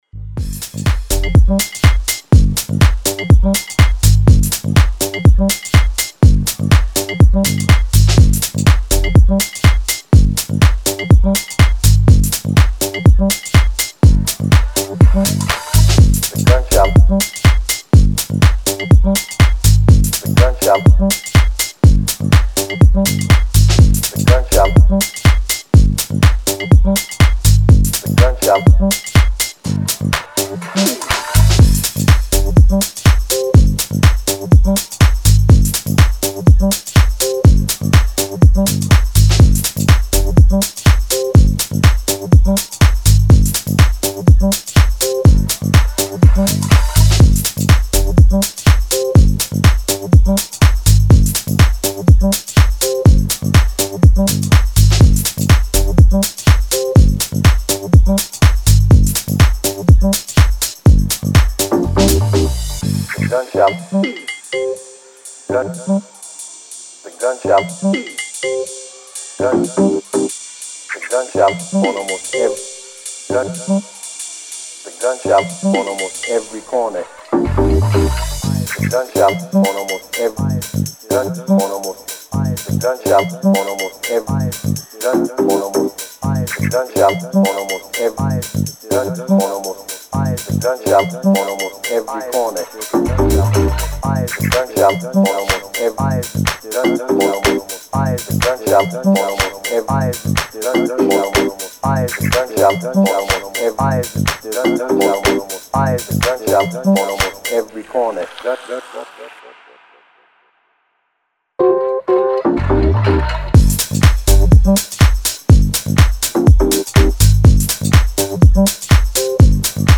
in a deep and groovy way
Style: Deep House / Tech House